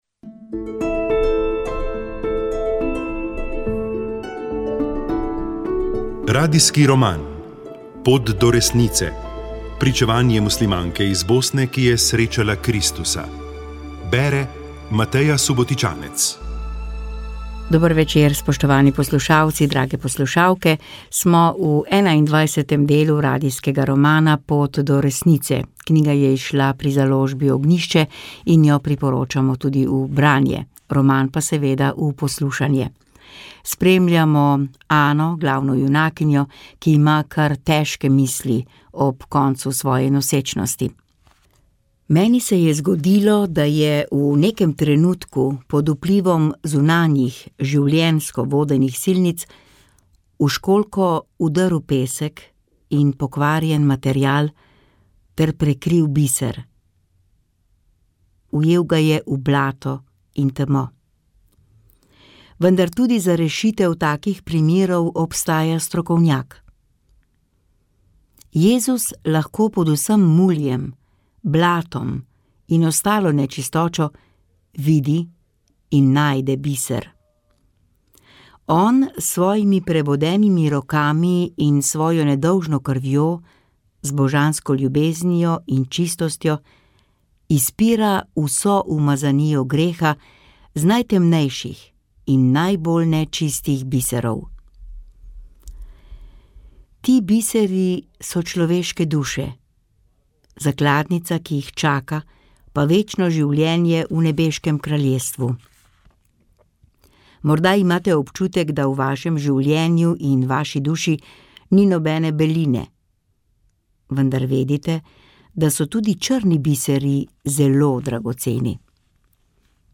Radijski roman